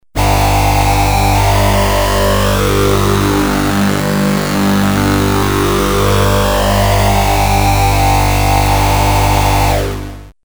No external effects added
Two analog oscillators play together while changing pulsewidth on the first one:
Monophonic. I like pulse width (163kB)